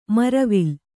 ♪ maravil